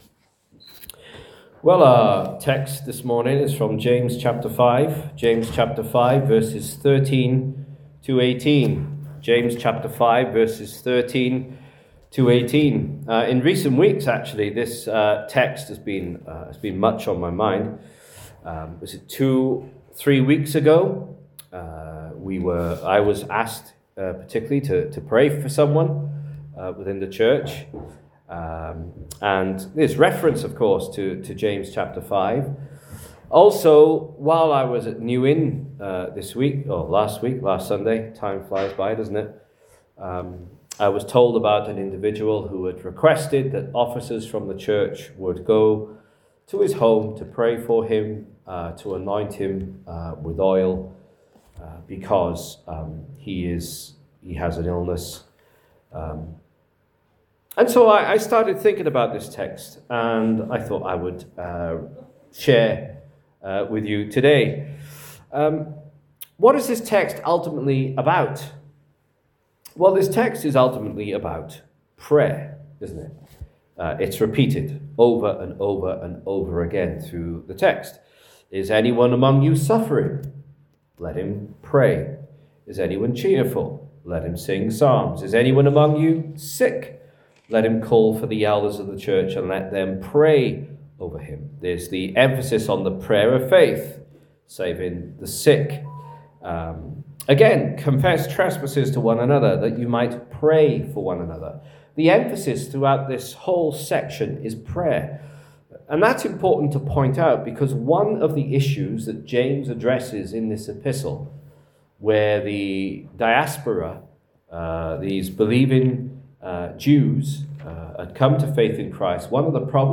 This sermon was preached at Union Croft Chapel on the morning of the 2nd November.